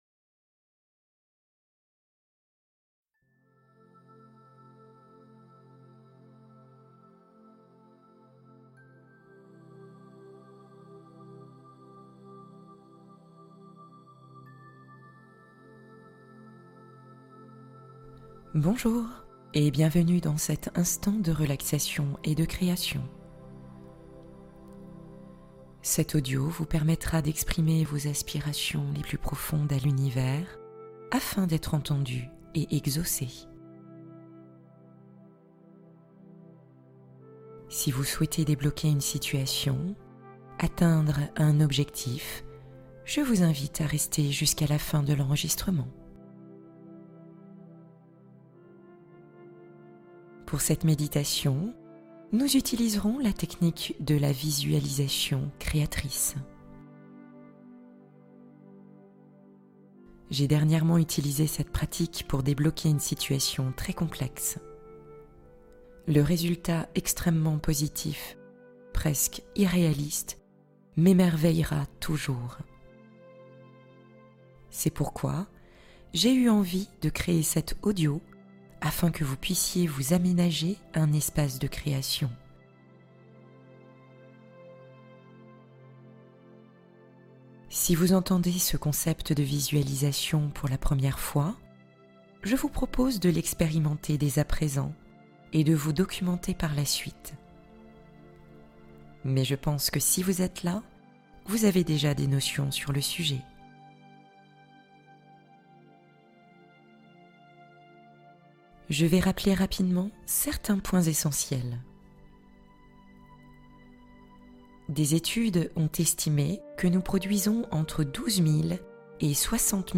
Sculptez votre futur idéal maintenant | Méditation guidée pour créer consciemment votre avenir